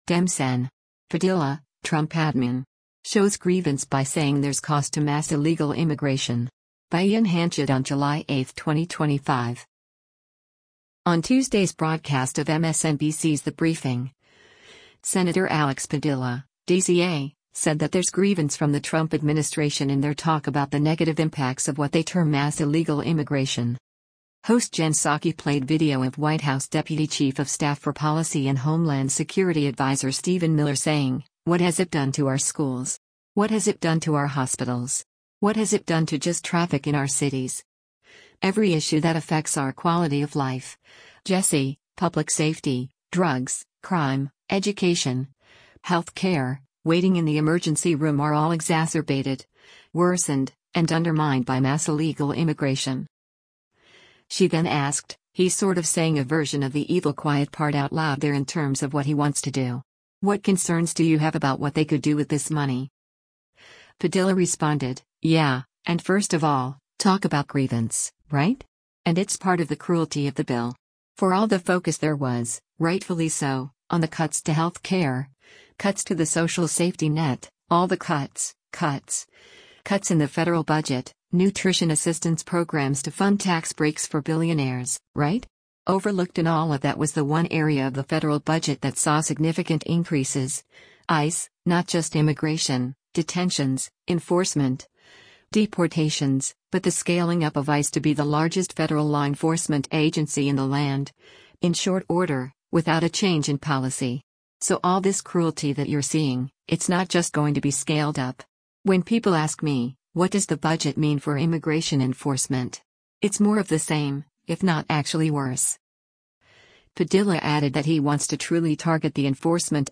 On Tuesday’s broadcast of MSNBC’s “The Briefing,” Sen. Alex Padilla (D-CA) said that there’s “grievance” from the Trump administration in their talk about the negative impacts of what they term “mass illegal immigration.”
Host Jen Psaki played video of White House Deputy Chief of Staff for Policy and Homeland Security Adviser Stephen Miller saying, “What has it done to our schools?